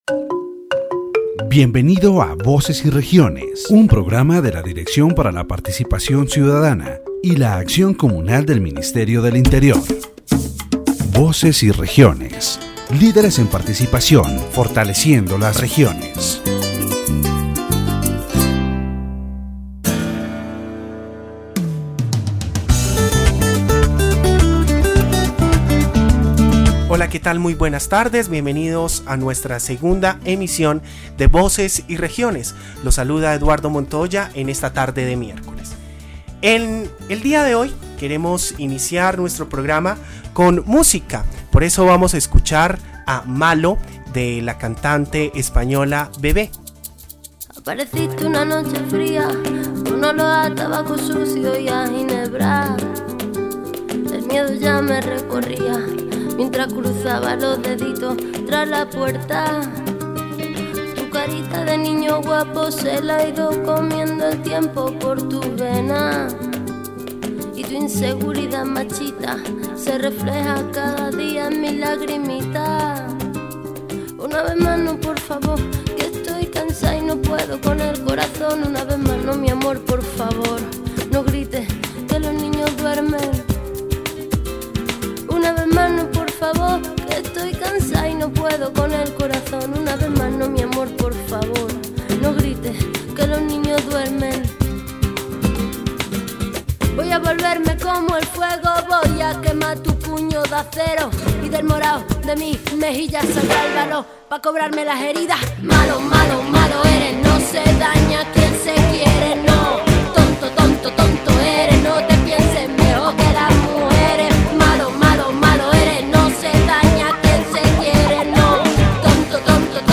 The radio program "Voices and Regions" of the Directorate for Citizen Participation and Community Action of the Ministry of the Interior addresses the issue of women's participation.